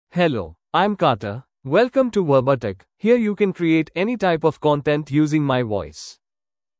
Carter — Male English (India) AI Voice | TTS, Voice Cloning & Video | Verbatik AI
MaleEnglish (India)
Carter is a male AI voice for English (India).
Voice sample
Listen to Carter's male English voice.
Carter delivers clear pronunciation with authentic India English intonation, making your content sound professionally produced.